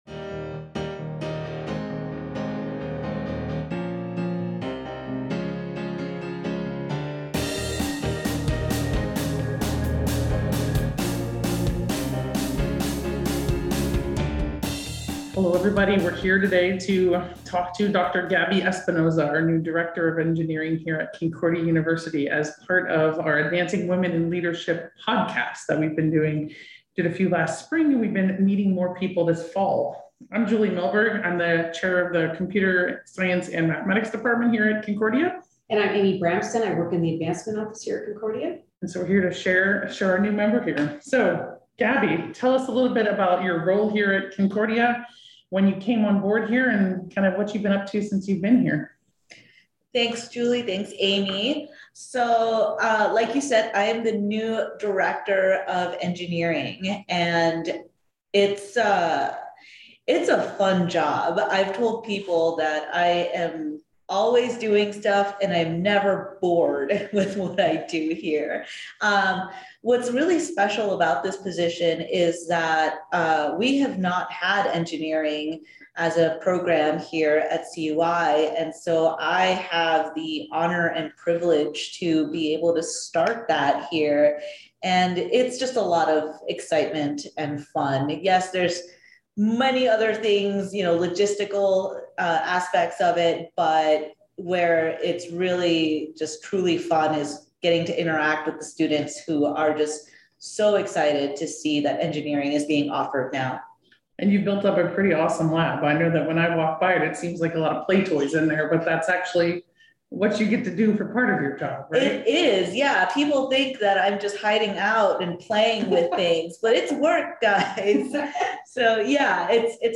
AWiL Interview